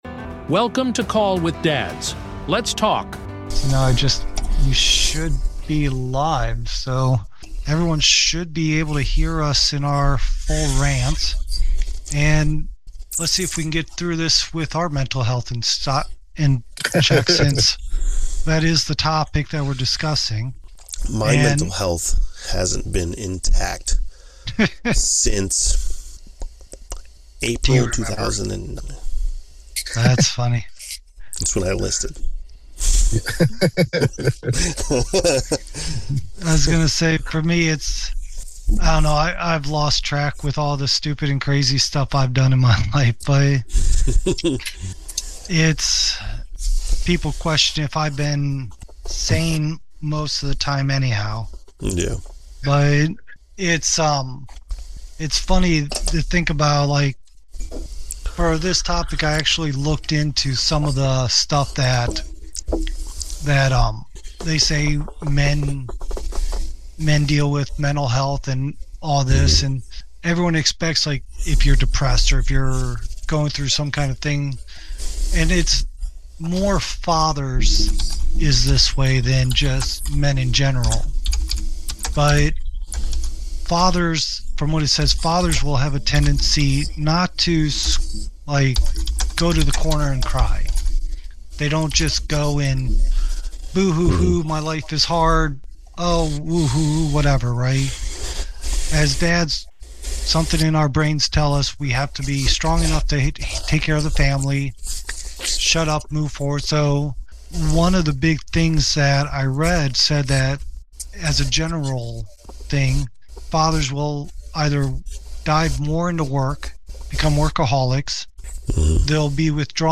Recorded live on February 25, 2026, the discussion dives into the unique pressures dads face—provider expectations, emotional suppression, declining testosterone, divorce impacts, isolation, and rising suicide risks—while sharing personal stories, societal critiques, and practical, no-nonsense advice.